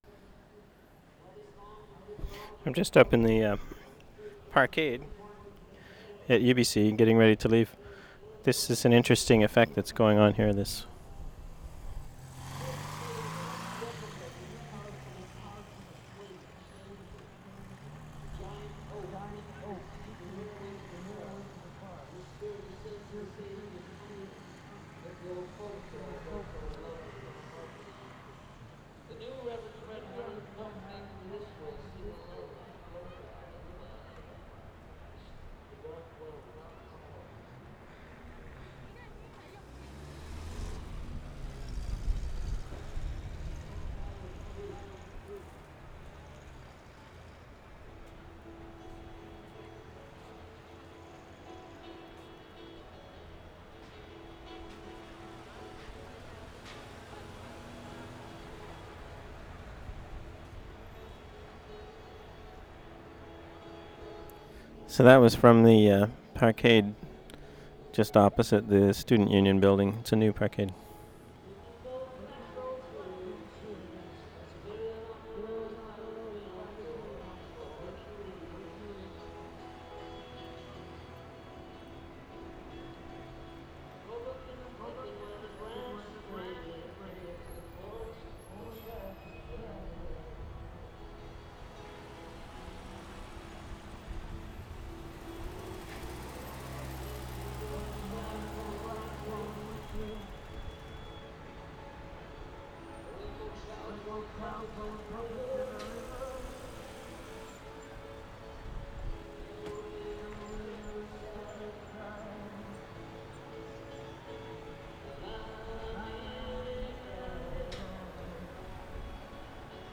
WORLD SOUNDSCAPE PROJECT TAPE LIBRARY
VANCOUVER, WEST SIDE , SEPT 9-10, 1993
parkade opposite SUB 3:40